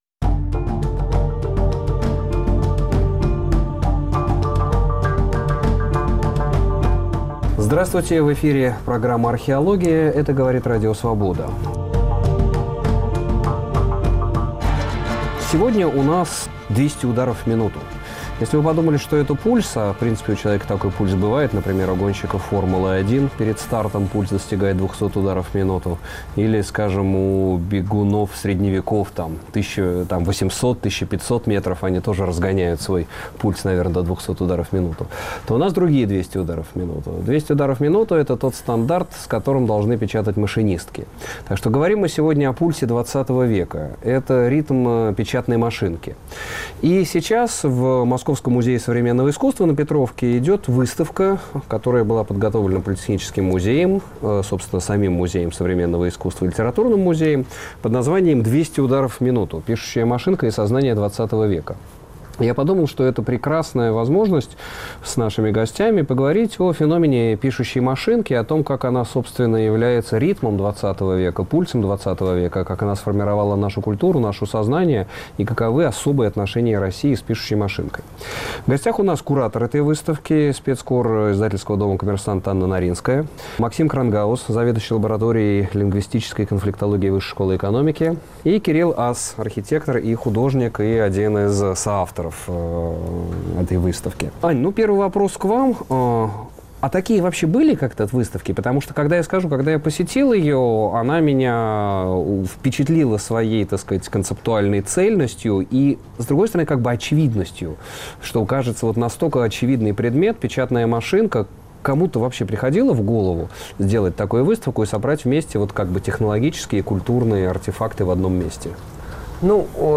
Пишущая машинка как символ ХХ века Гости: Анна Наринская, специальный корреспондет ИД "Коммерсантъ"; Максим Кронгауз, лингвист, профессор ВШЭ